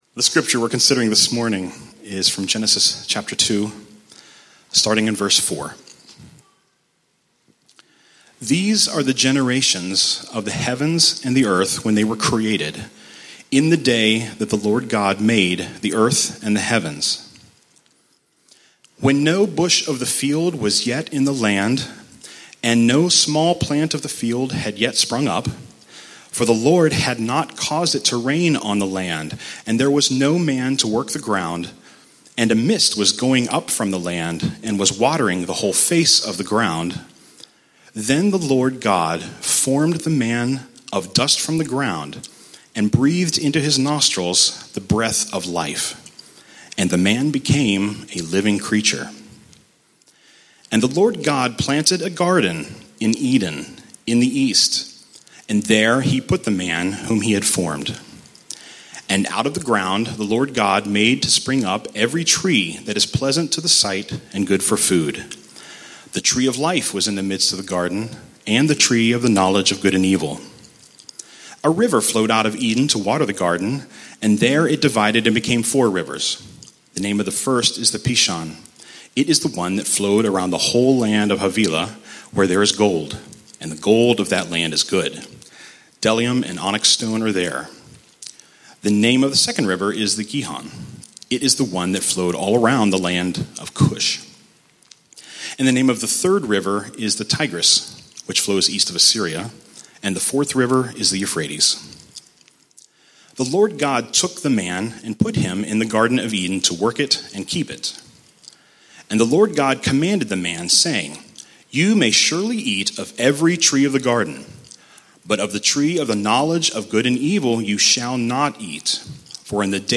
Download the weekly Bible Study that goes with this sermon.